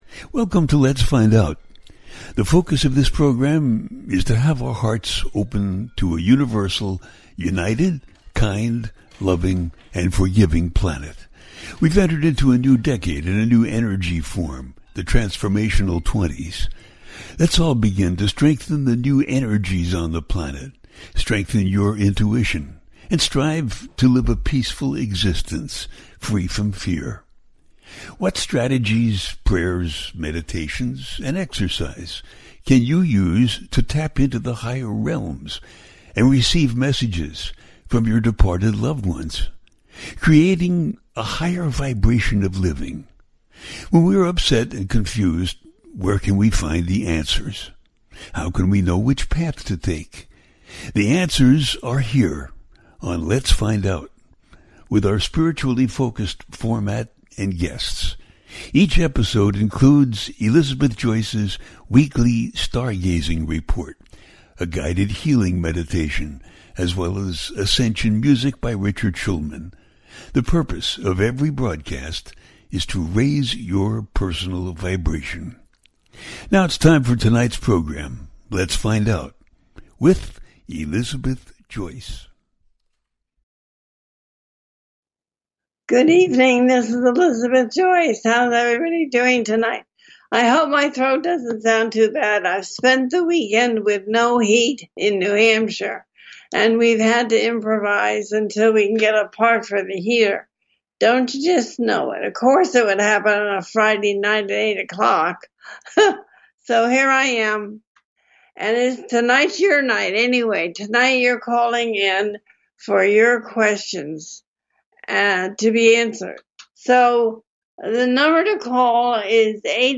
It’s Time For You, Question and Answers, November Energies For Each Sign, A teaching show.
The listener can call in to ask a question on the air.
Each show ends with a guided meditation.